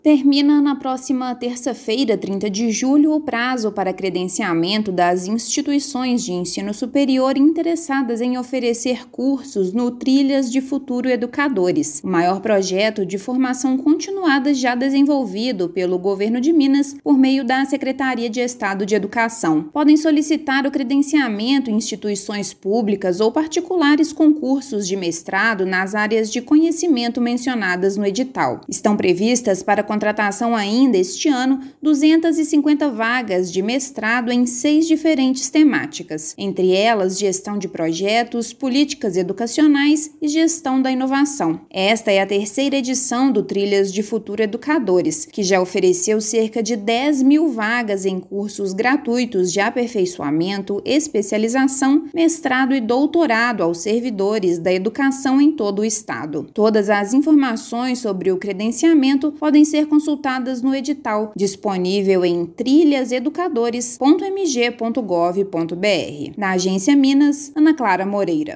Projeto de formação continuada é o maior já desenvolvido pela pasta. Prazo se encerra na próxima terça-feira (30/7). Ouça matéria de rádio.